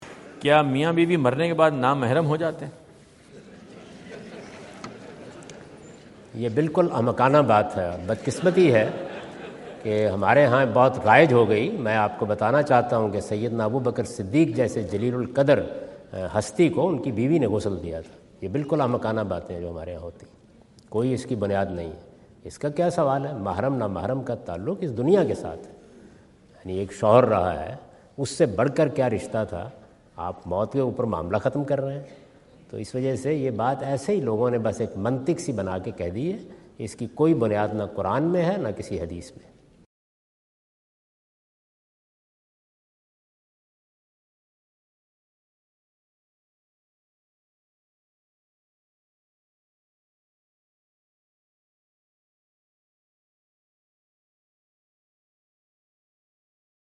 Javed Ahmad Ghamidi answer the question about "Does Death Nullify Marriage Relationship?" asked at North Brunswick High School, New Jersey on September 29,2017.
جاوید احمد غامدی اپنے دورہ امریکہ 2017 کے دوران نیوجرسی میں "کیا موت شادی کے رشتے کو ختم کر دیتی ہے؟" سے متعلق ایک سوال کا جواب دے رہے ہیں۔